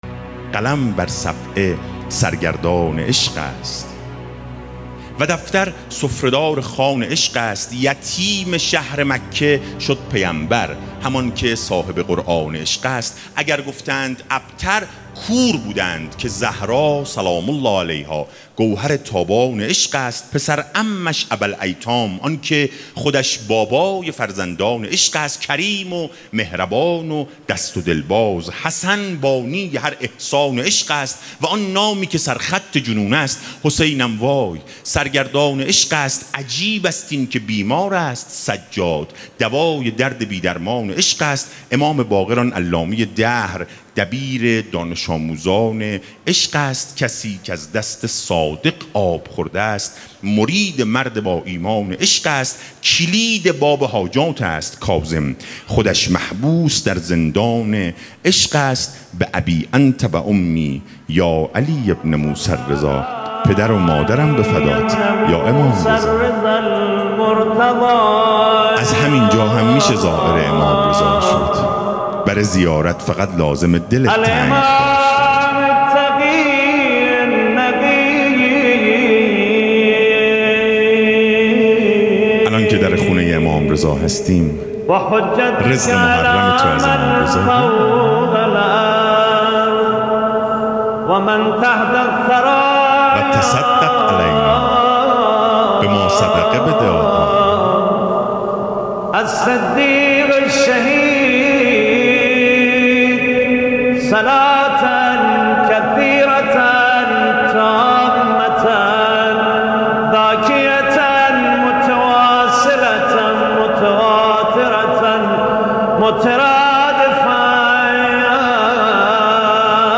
شعر خوانی صابر خراسانی | عید سعید غدیر خم | هیئت غدیریه اصفهان